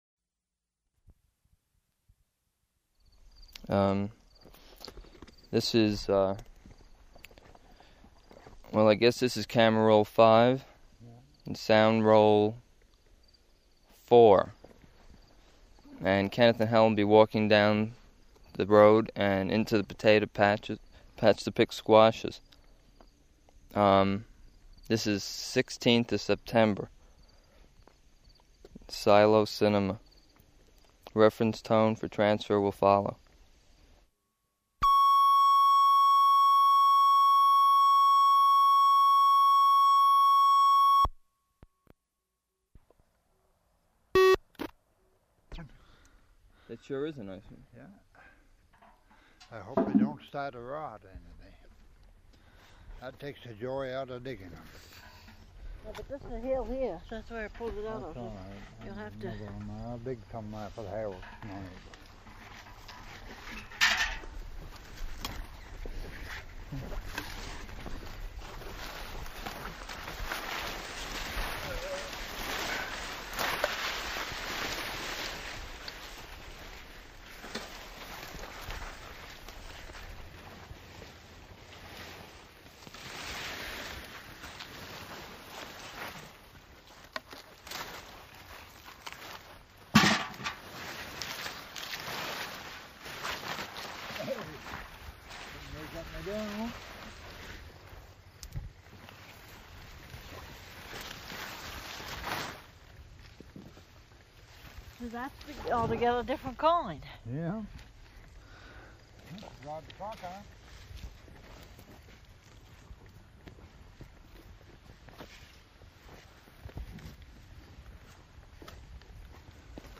picking squash from their garden and placing them into metal pails.
Format 1 sound tape reel (Scotch 3M 208 polyester) : analog ; 7 1/2 ips, full track, mono.